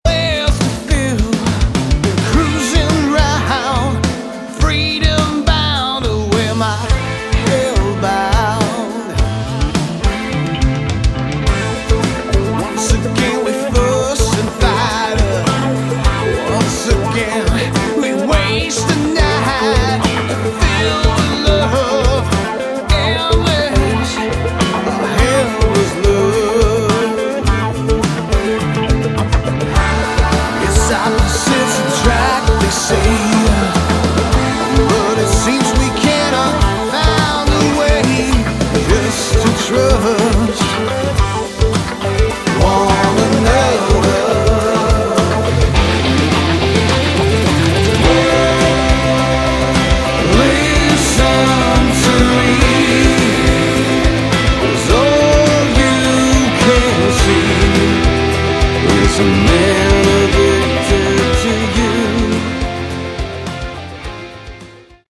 Category: Hard Rock
guitars, bass, keyboards
drums